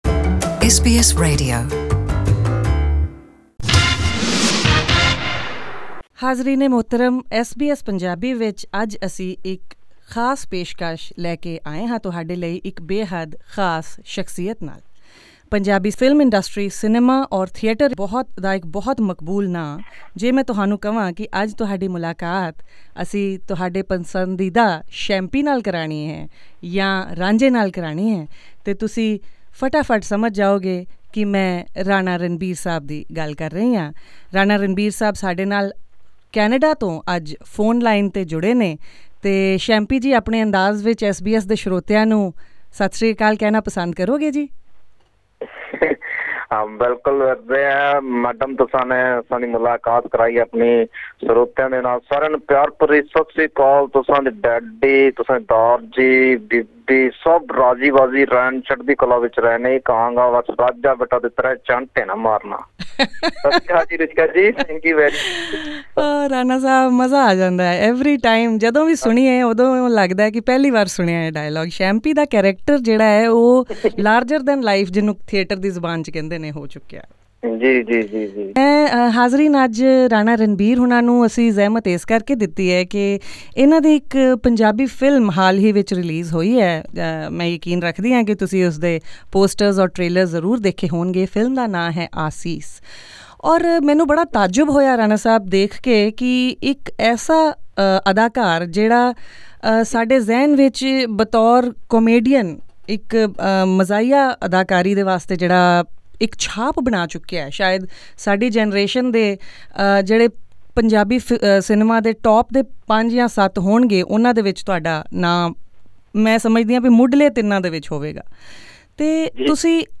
So with Asees , I was able to realise this dream,” Ranbir told SBS Punjabi in a wide-ranging interview from his Canada home over the phone as he is now a Canadian resident. He talks to our listeners in his most famous character, Shampy’s style and also exposes his pleasantly-surprising intellectual side.